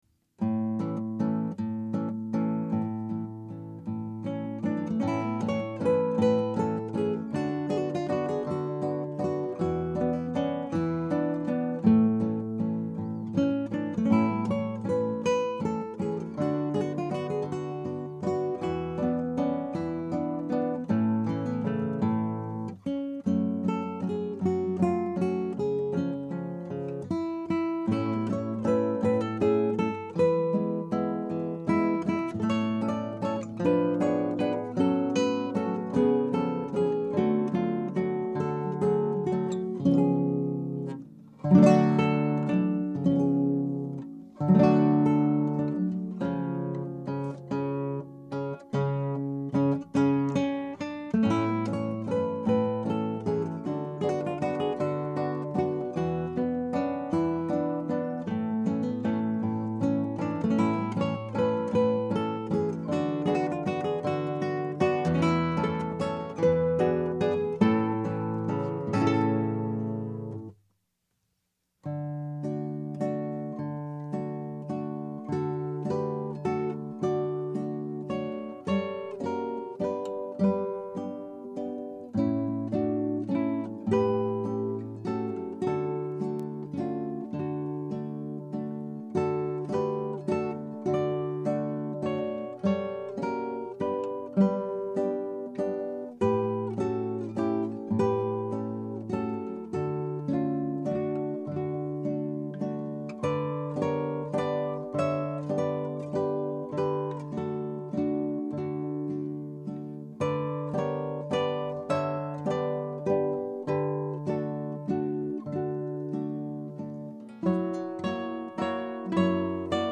Scraps from the Operas arranged for Two Guitars
Scrap 1: Andantino.
Scrap 2 (1:11): Piu lento.
Scrap 3 (3:29): Tempo primo.